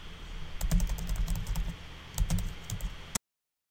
音频1键板打字
描述：键盘打字
Tag: 键盘 mus152 弗利